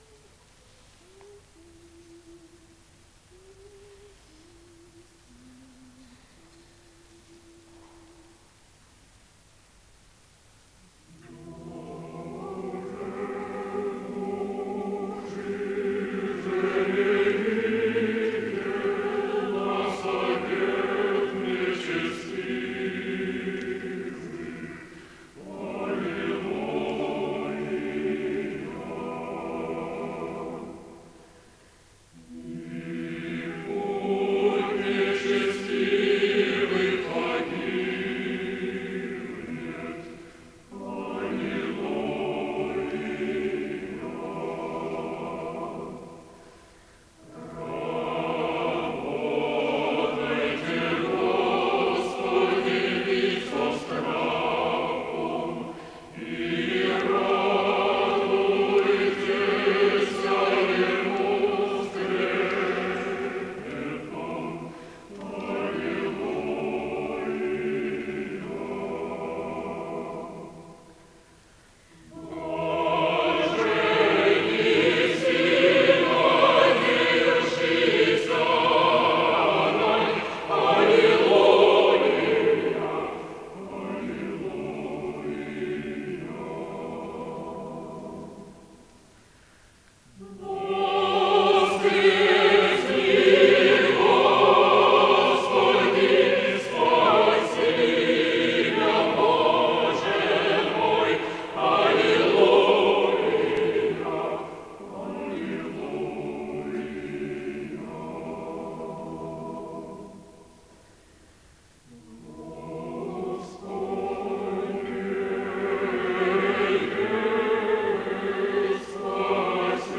Uppståndelsekyrkan. Konsert från Hedvig Eleonora kyrka i Stockholm, 1985.